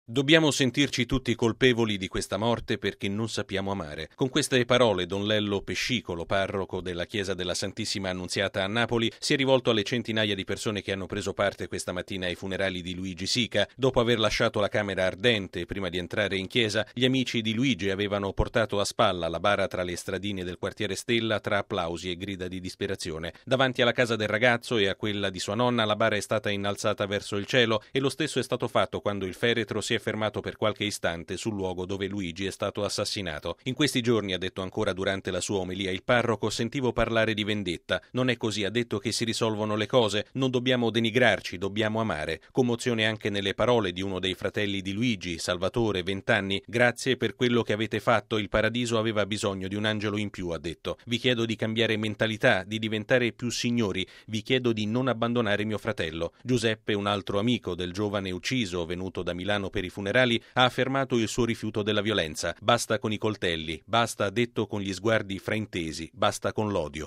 servizio